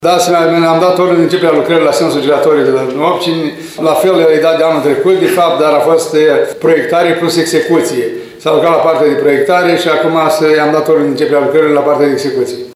Parcul de la intersecția bulevardului 1 Mai cu strada Calea Obcinilor va fi reconfigurat, după cum a declarat primarul ION LUNGU.